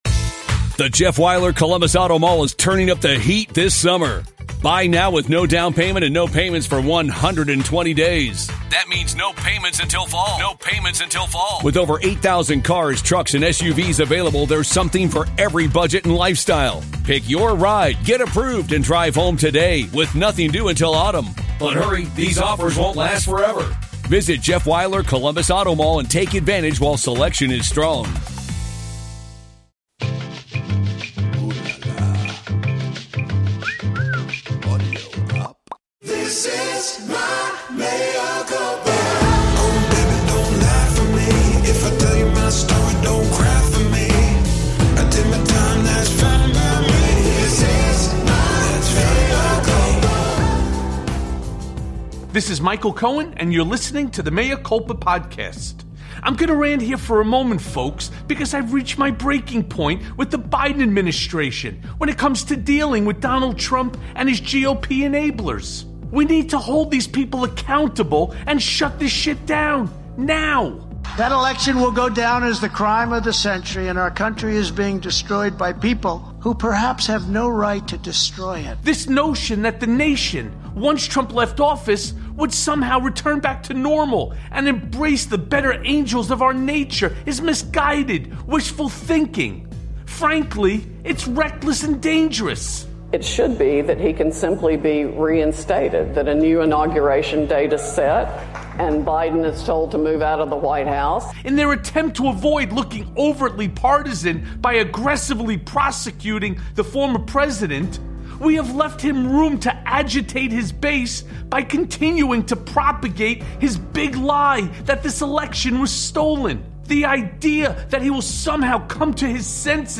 A Conversation With MSNBC’s Joy Reid